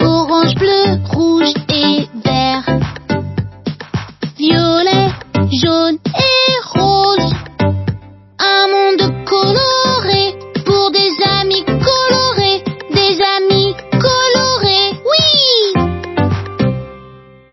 voix off femme linkimal Fisher Price beaver
3 - 27 ans - Mezzo-soprano Soprano